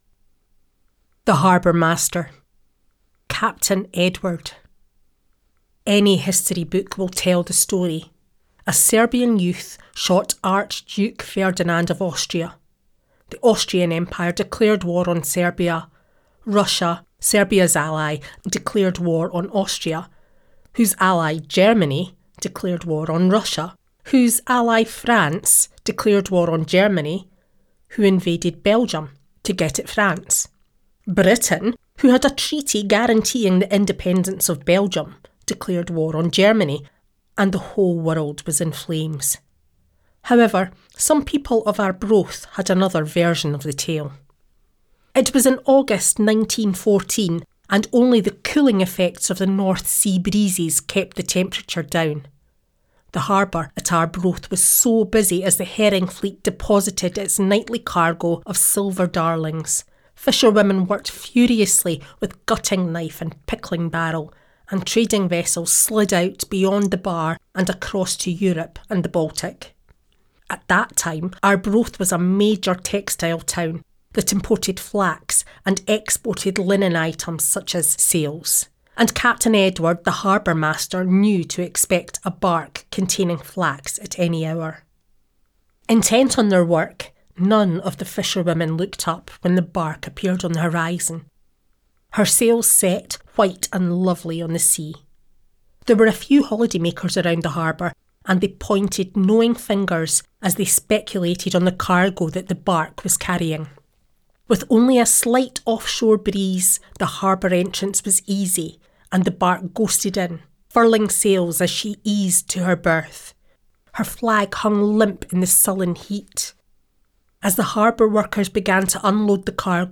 Trained Actress with natural, friendly and authentic Scottish accent
Scottish, Edinburgh, Glasgow, Highland, Irish
Middle Aged